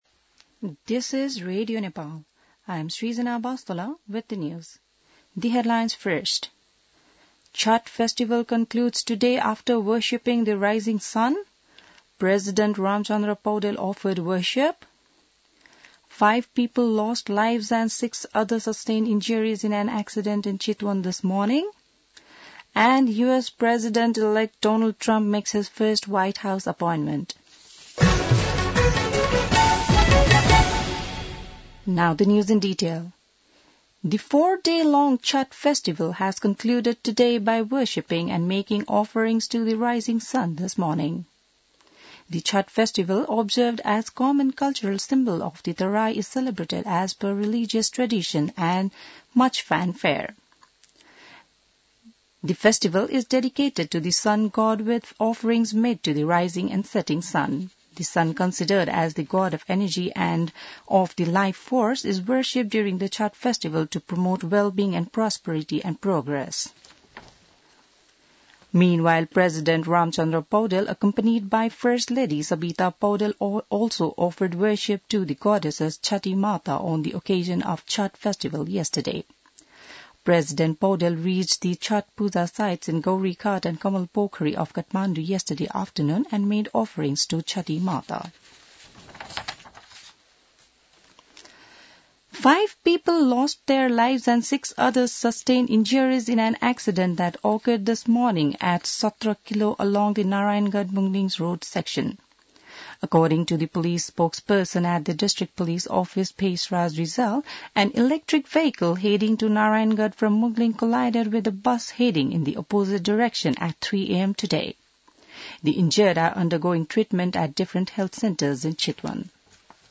बिहान ८ बजेको अङ्ग्रेजी समाचार : २४ कार्तिक , २०८१